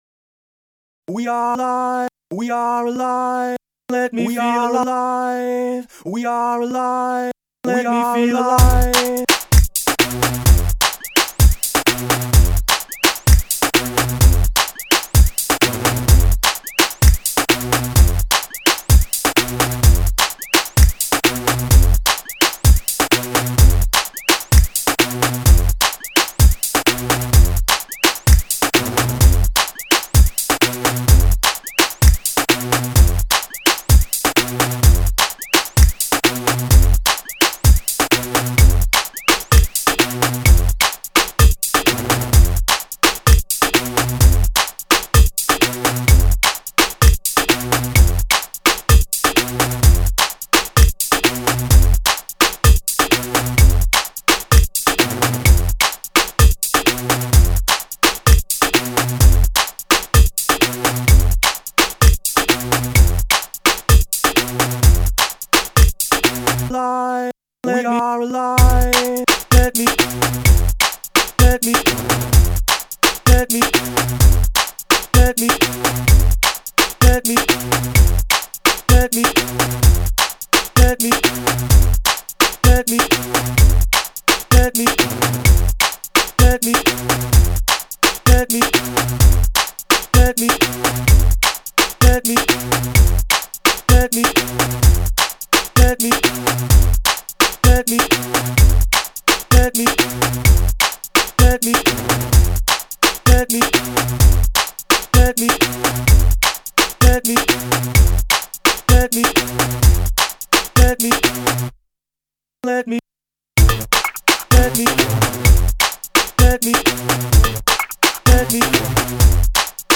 EXCLUSIVE REMIX!